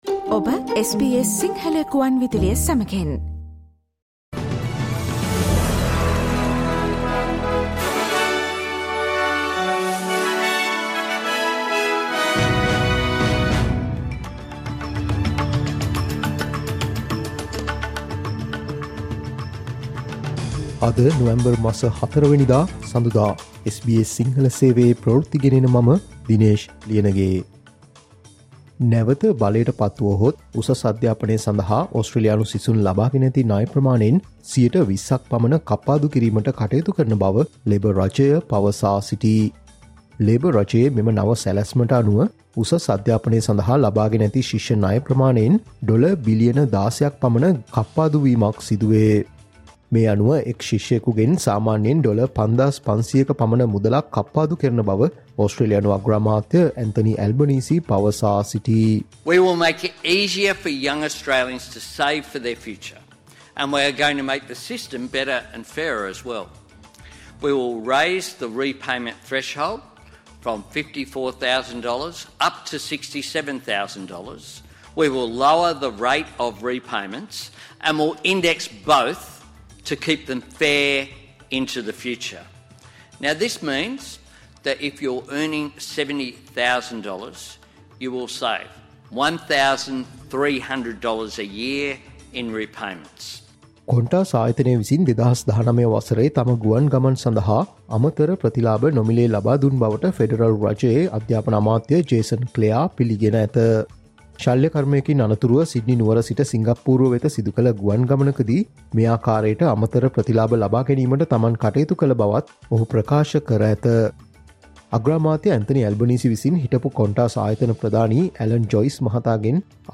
Australia's news in Sinhala.